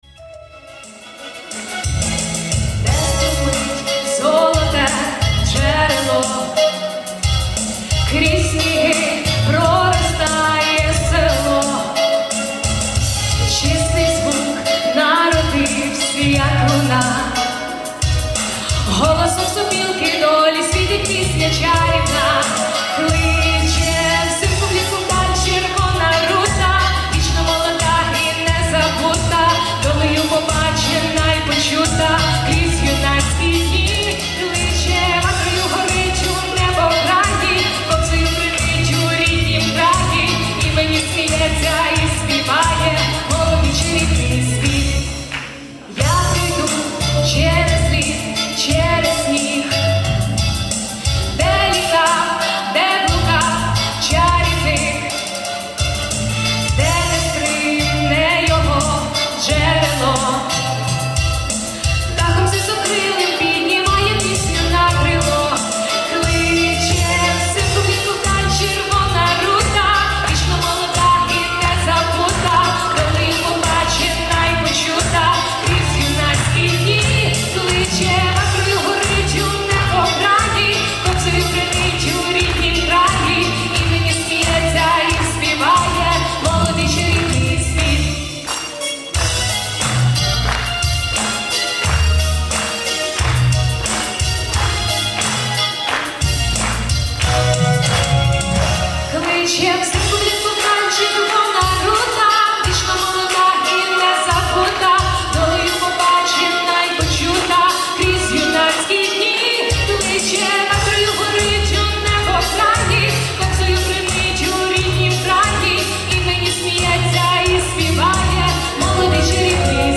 На днях я побывал на одном мероприятии и там девушка пела песню которую я слышал вроде в начале 90 тых.